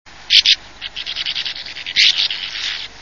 Brzegówka - Riparia riparia
głosy